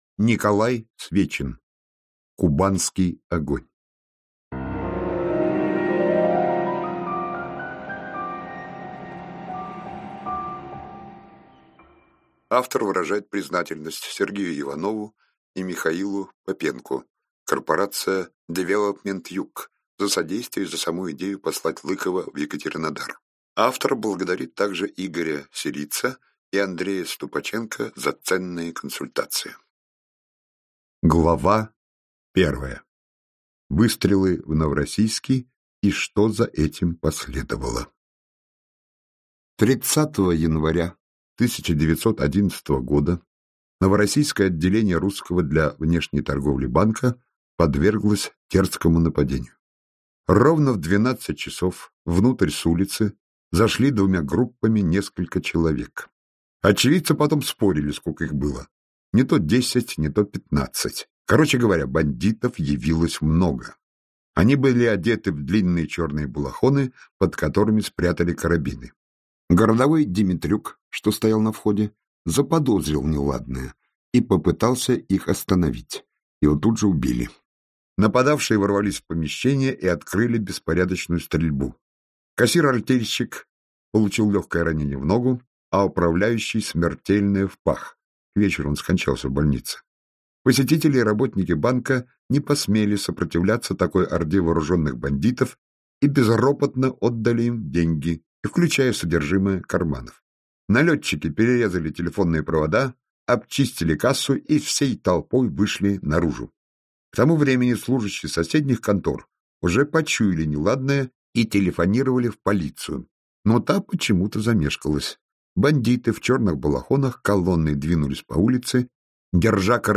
Аудиокнига Кубанский огонь - купить, скачать и слушать онлайн | КнигоПоиск
Аудиокнига «Кубанский огонь» в интернет-магазине КнигоПоиск ✅ в аудиоформате ✅ Скачать Кубанский огонь в mp3 или слушать онлайн